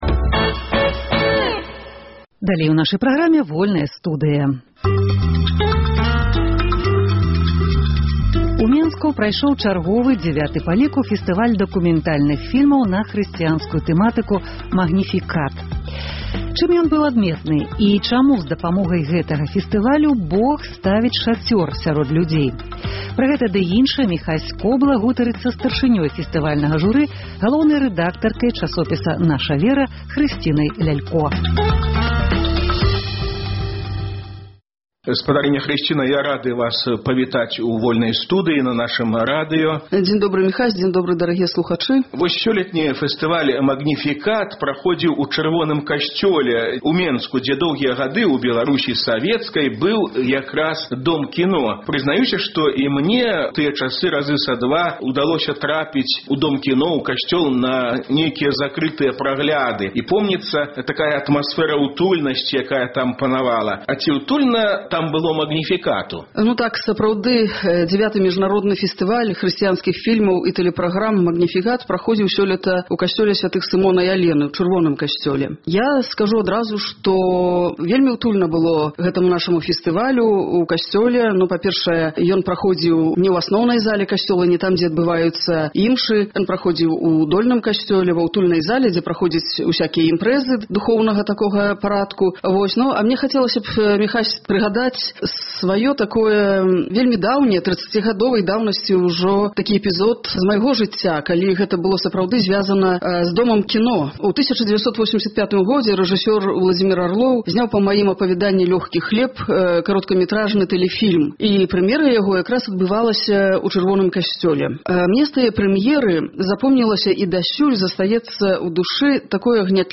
У чым мітрапаліт Філярэт ішоў супраць сьвецкай улады? Ці спрыяў ён беларусізацыі падначаленай яму царквы? Пра гэта гутарка